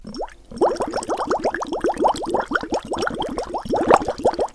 针对吸管水杯吹气声音的PPT演示模板_风云办公